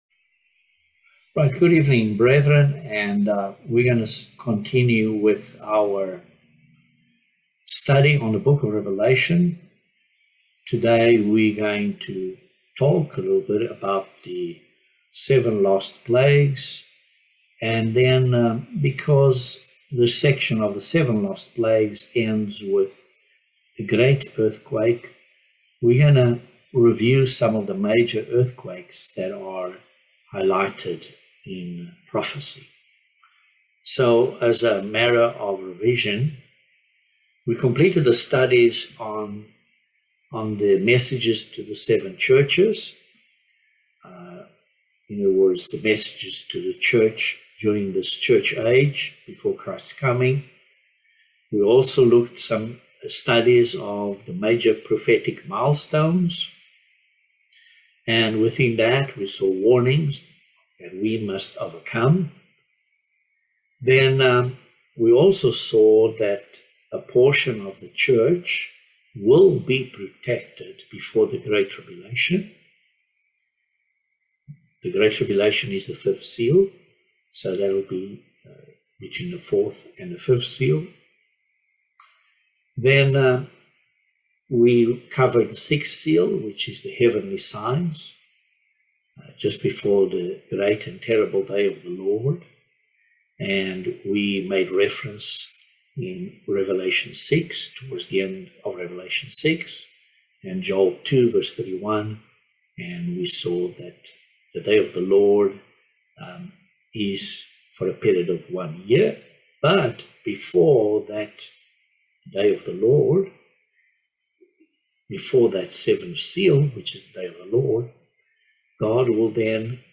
Bible Study No 24 of Revelation